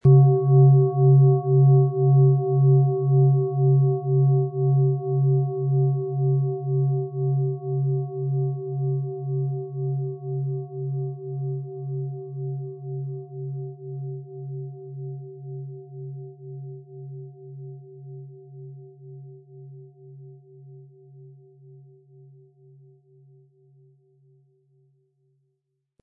Planetenschale® Offen und Weit fühlen & Werde erfolgreich mit OM-Ton & Jupiter, Ø 19,2 cm inkl. Klöppel
• Tiefster Ton: Jupiter
Sie möchten den schönen Klang dieser Schale hören? Spielen Sie bitte den Originalklang im Sound-Player - Jetzt reinhören ab.
PlanetentöneOM Ton & Jupiter
SchalenformOrissa
MaterialBronze